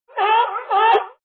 seals1.mp3